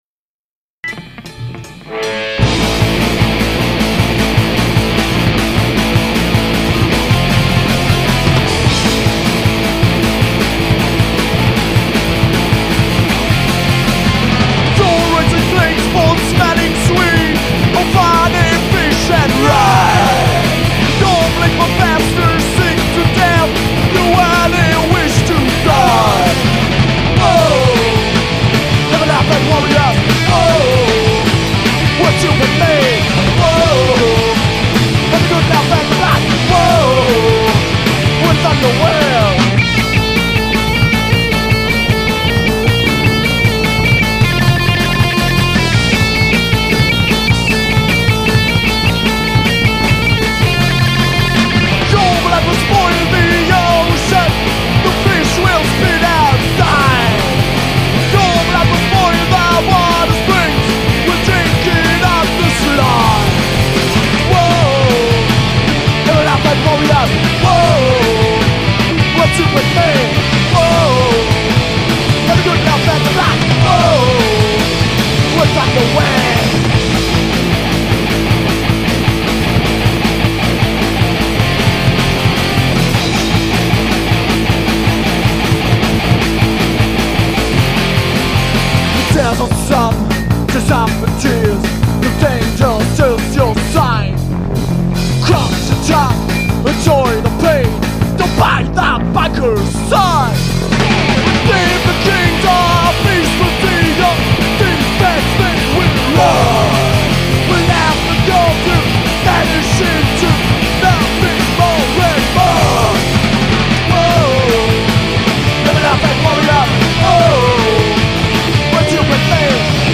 Live in der KVU: Mitschnitte vom Konzert am 11.12.2004